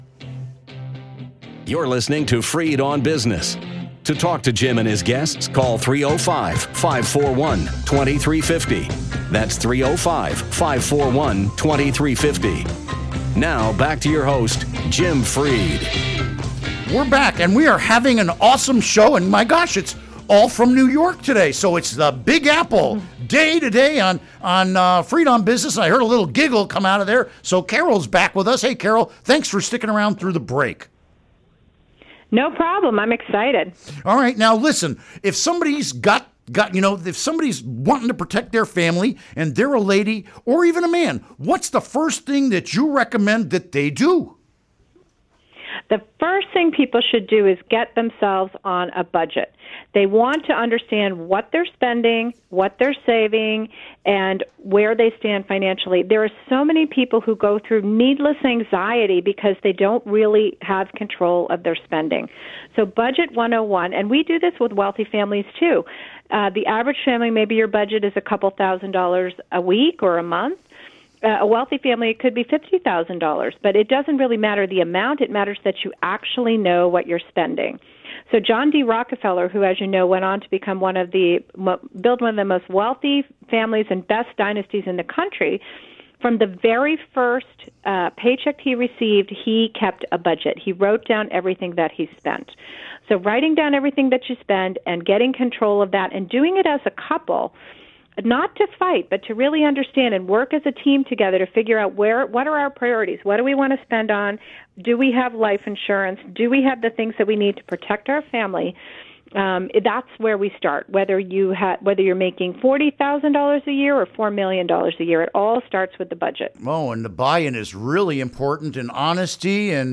Interview Segment Episode 393: 11-10-16 Click here to download Part 1 (To download, right-click and select “Save Link As”.)